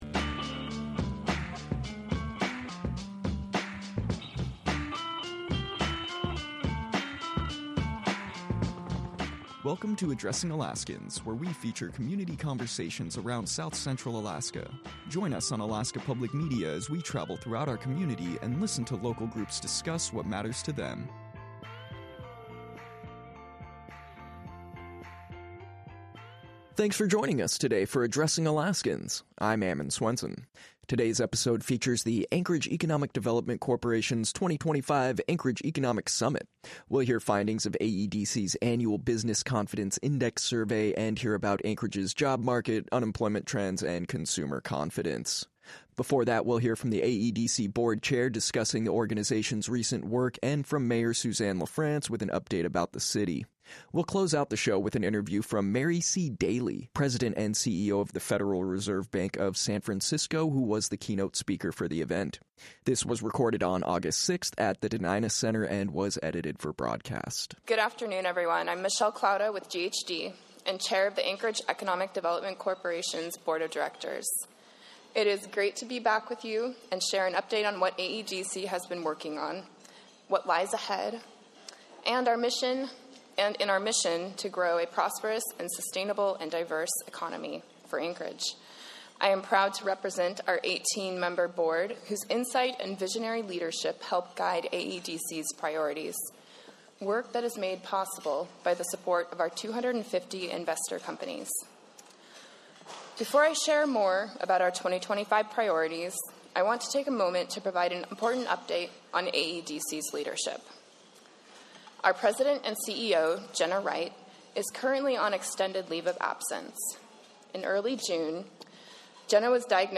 1 A conversation with Everardo González: The Disappeared of History Global Webinar Series 1:00:02
1 A conversation with Everardo González: The Disappeared of History Global Webinar Series 1:00:02 Play Pause 9d ago 1:00:02 Play Pause Play later Play later Lists Like Liked 1:00:02 In this webinar, we spoke to Everardo González, a Mexican director who is considered one of the strongest voices in the documentary genre in Latin America.Everardo's filmography includes Pulque Song (2003), The Old Thieves (2007), The Open Sky (2011), Drought (2011) and El Paso (2015), all screened and awarded at various festivals like Berlin, IDFA…